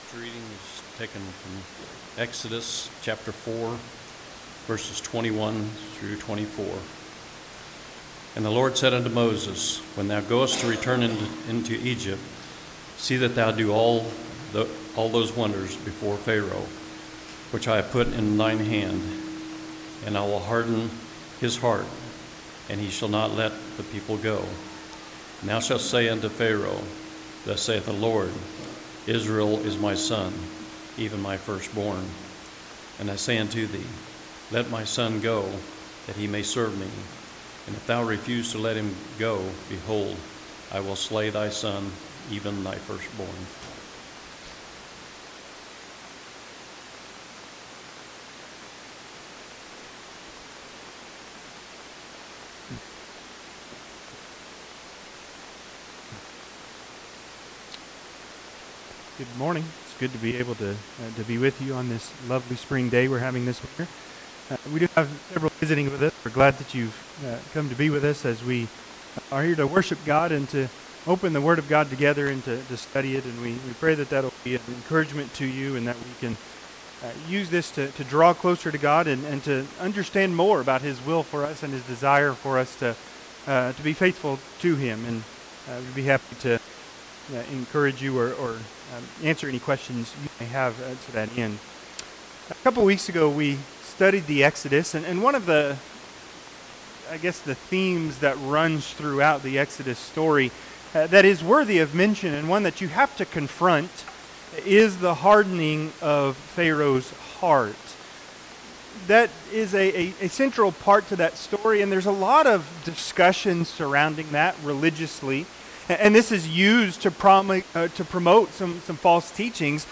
Service Type: Sunday AM Topics: God's Word , Hearts , The effect of God's Word « The Sermon on the Mount